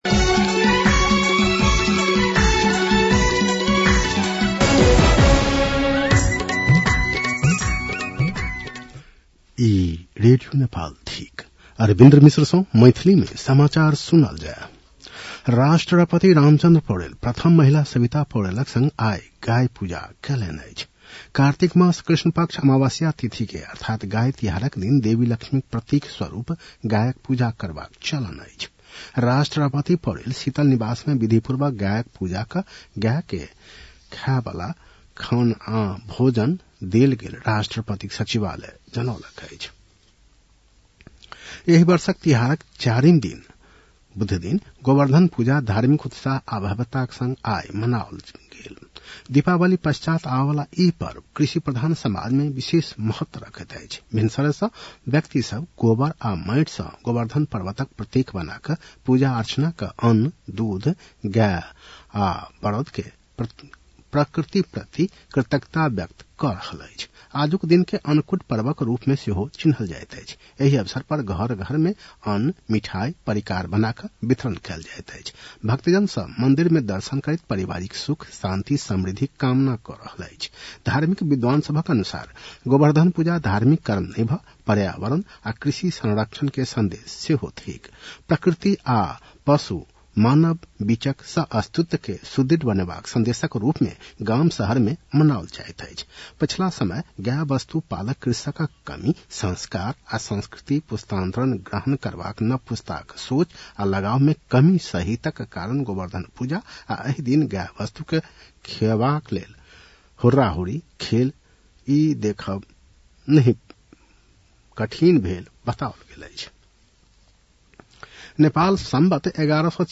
मैथिली भाषामा समाचार : ५ कार्तिक , २०८२